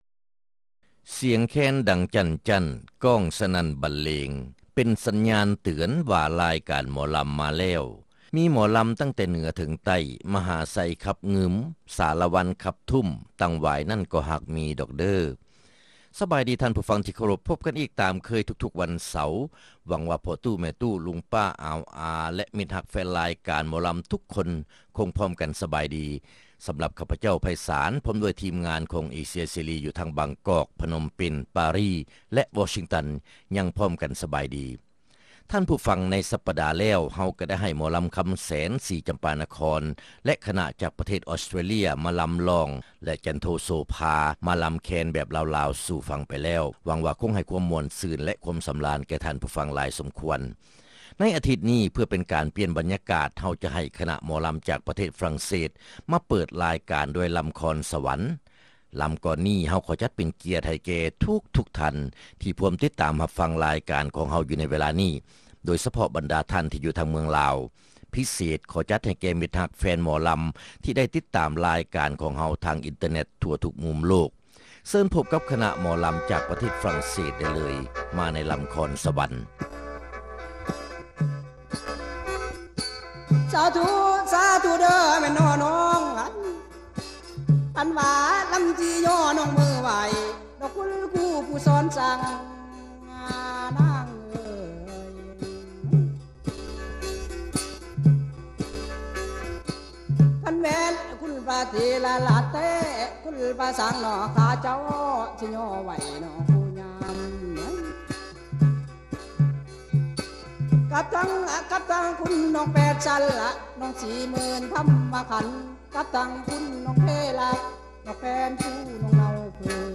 ຣາຍການໜໍລຳ ປະຈຳສັປະດາ ວັນທີ 8 ເດືອນ ທັນວາ ປີ 2006